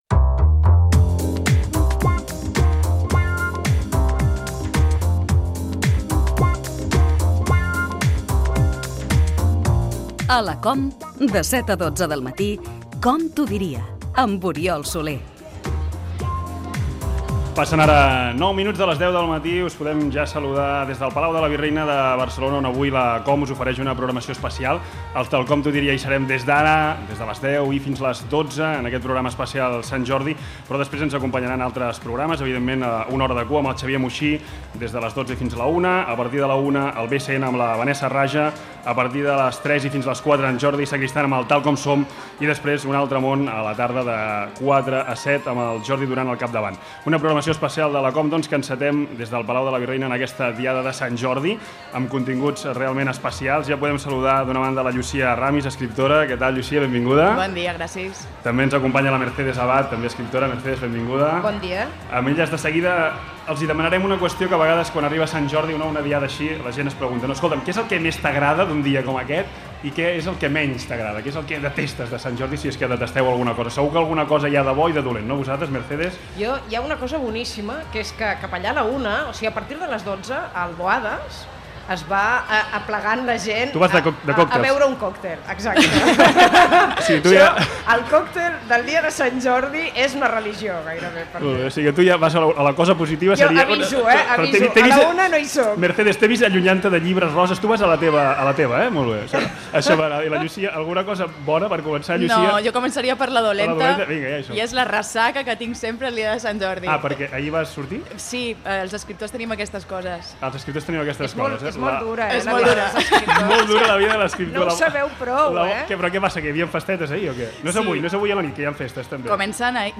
Fragment d'un especial Sant Jordi des del Palau de la Virreina. Indicatiu del programa, hora, sumari del programa.
Info-entreteniment